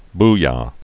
(b, -yə)